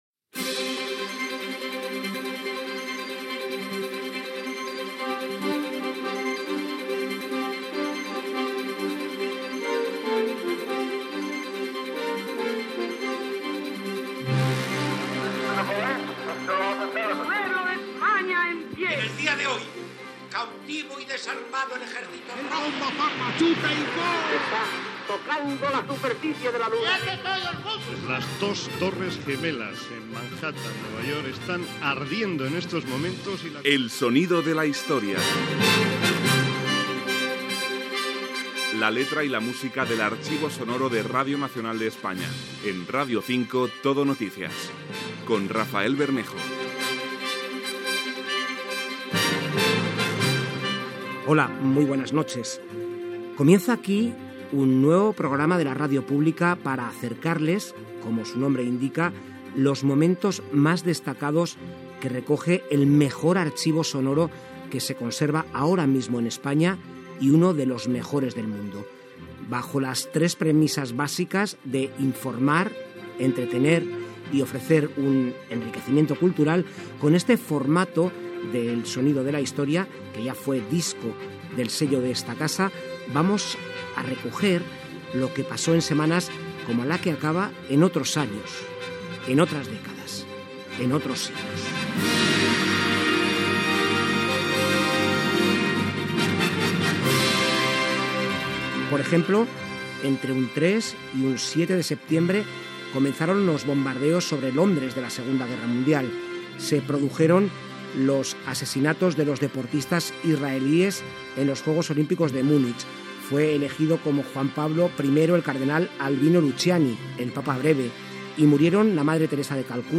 Careta del programa, presentació del primer programa, sumari de continguts, reportatge sobre la Segona Guerra Mundial, una cançó de 1940
Divulgació